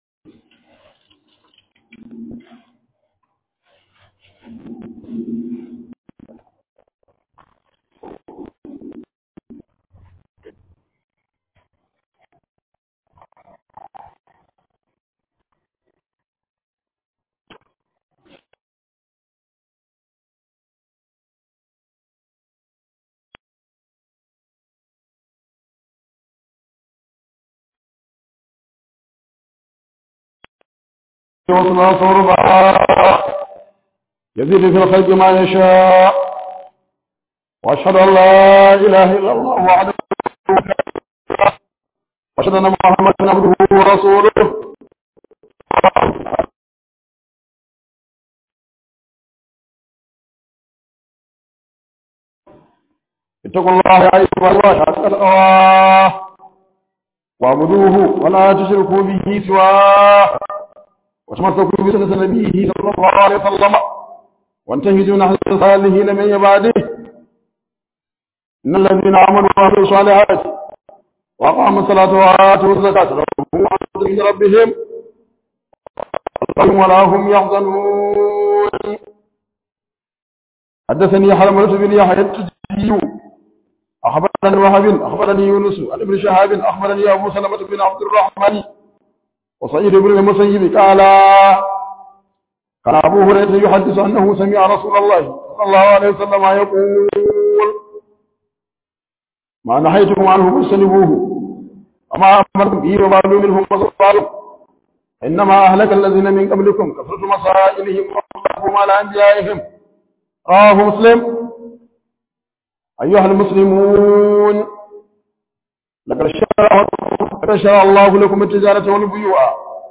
008 Khudba Jumaa 07_02_2025.mp3